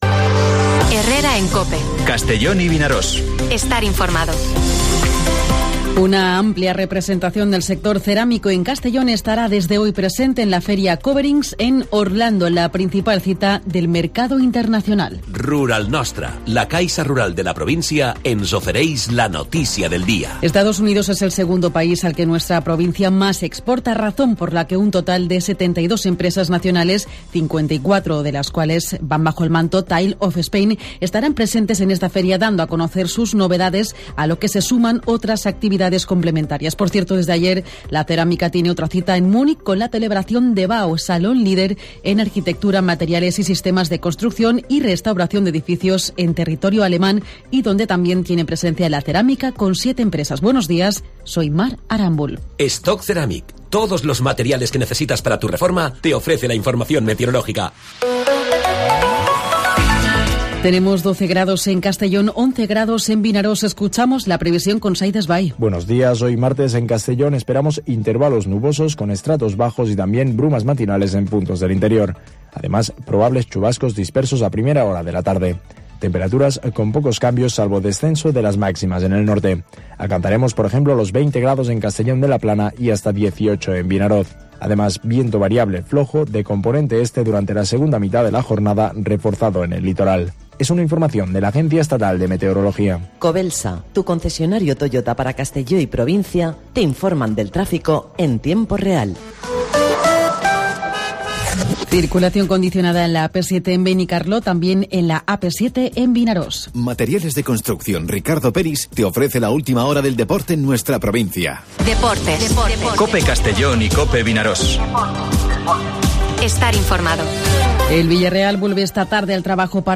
Informativo Herrera en COPE en la provincia de Castellón (18/04/2023)